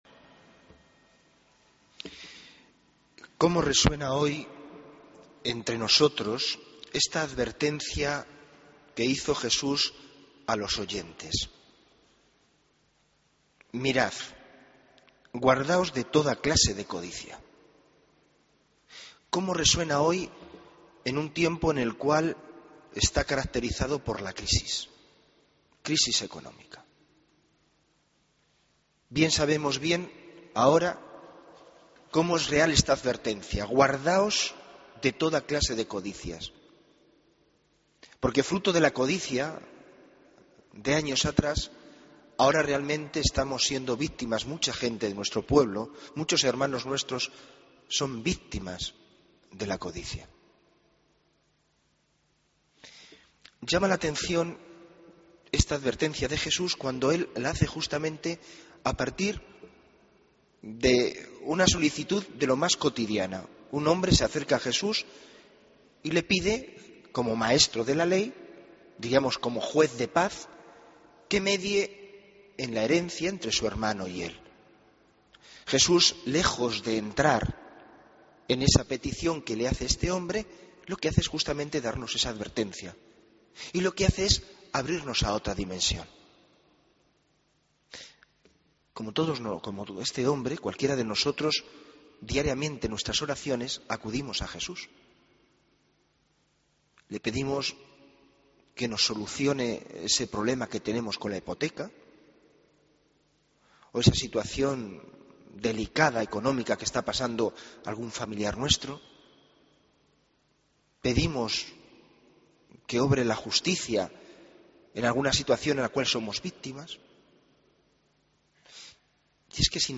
Homilía del 4 de agosto de 2013